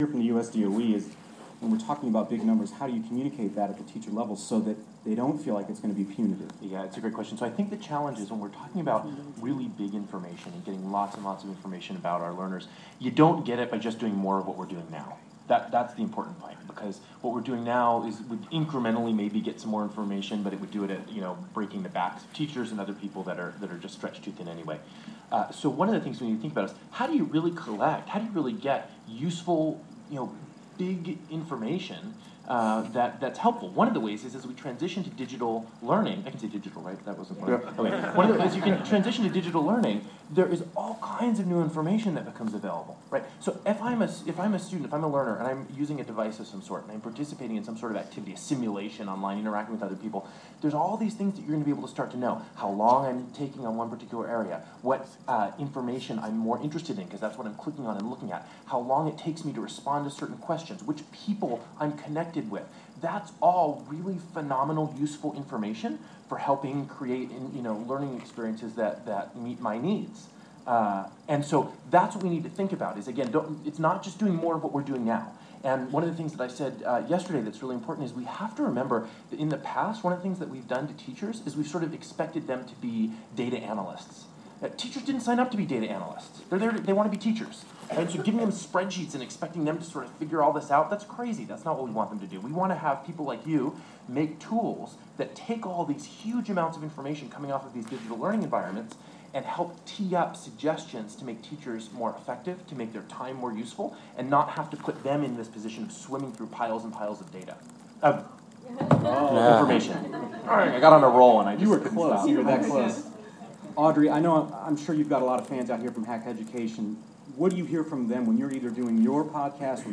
sxswedu